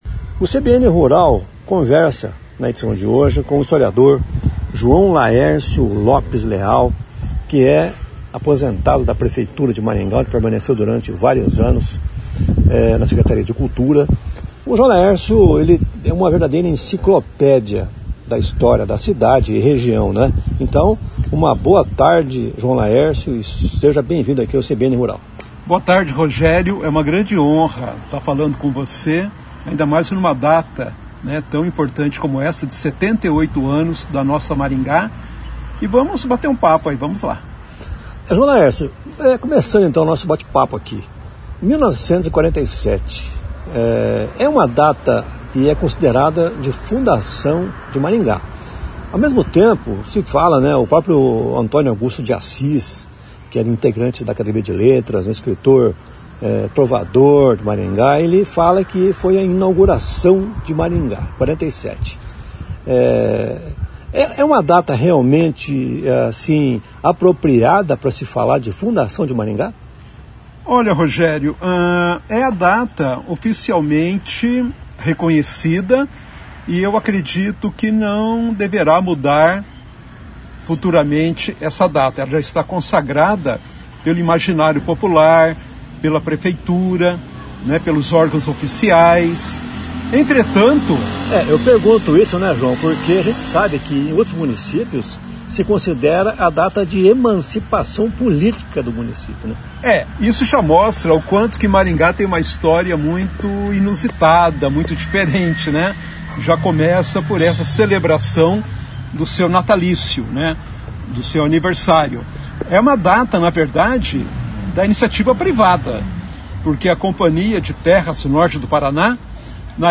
Nesta edição, CBN Rural conversou com o historiador